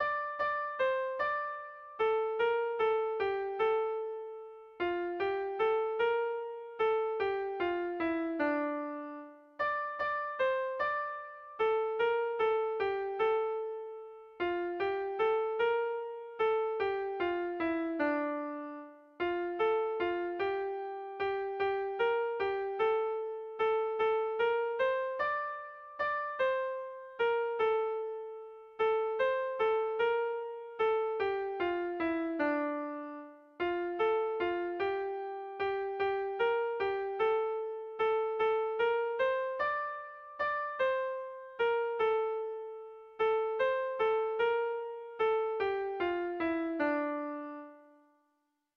Erlijiozkoa
ABDE